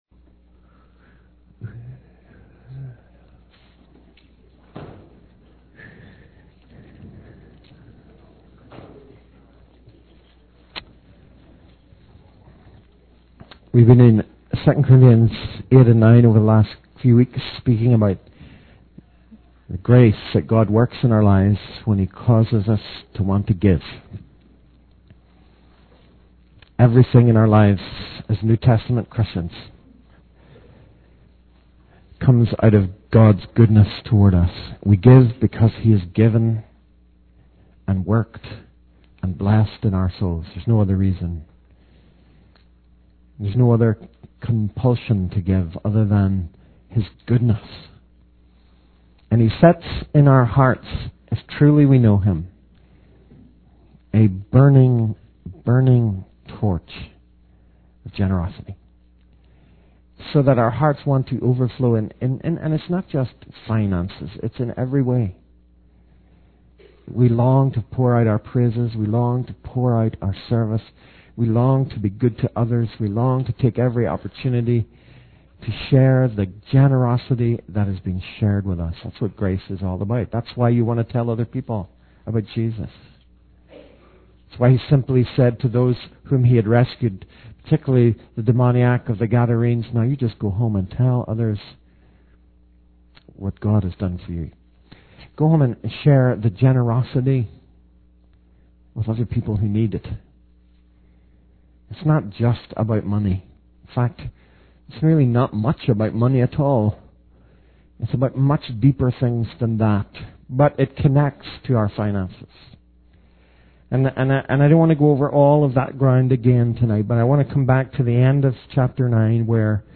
In this sermon, the preacher emphasizes the importance of generosity and sharing God's blessings with others. He highlights the story of the demoniac of the Gadarenes, who was instructed by Jesus to go home and tell others about what God had done for him.